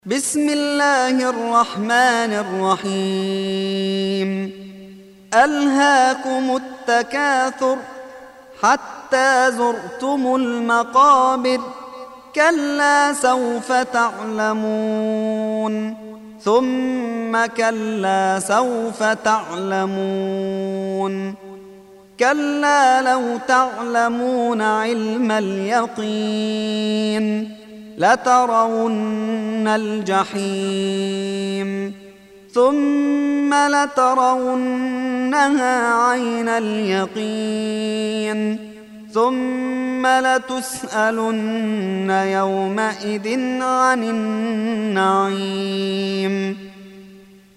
102. Surah At-Tak�thur سورة التكاثر Audio Quran Tarteel Recitation